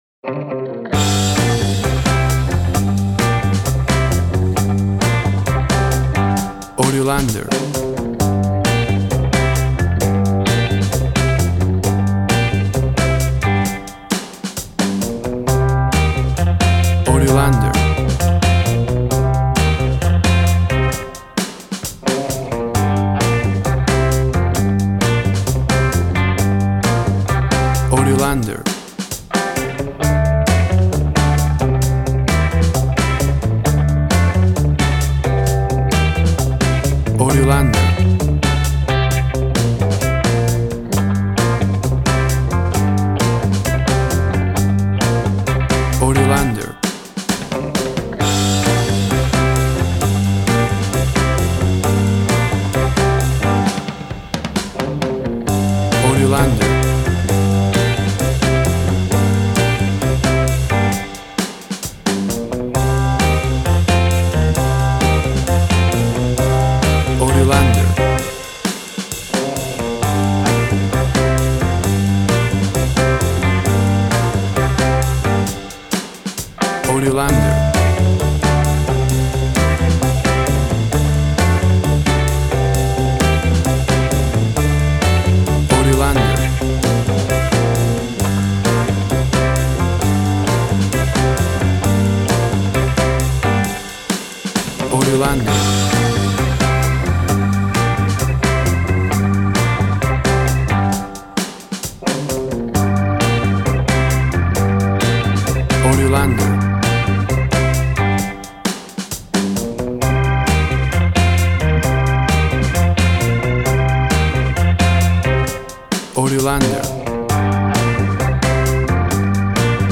Rock pop of the 60´s vintage.
Tempo (BPM) 135